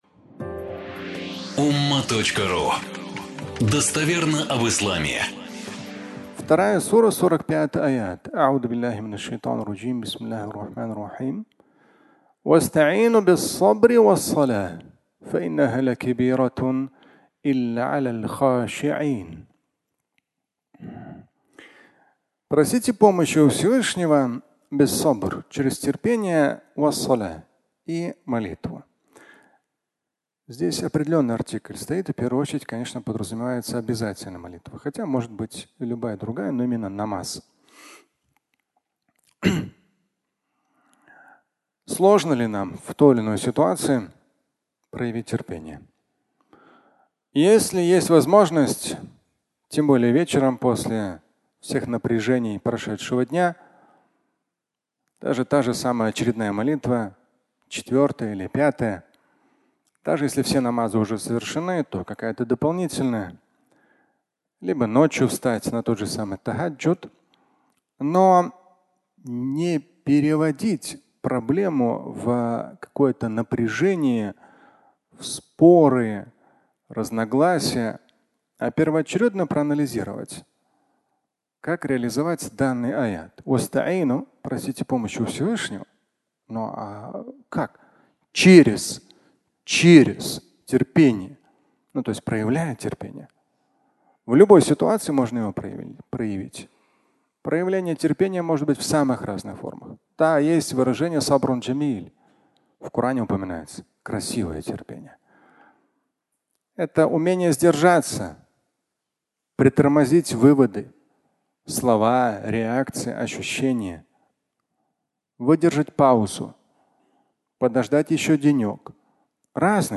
Актуально ежедневно (аудиолекция)
Фрагмент пятничной проповеди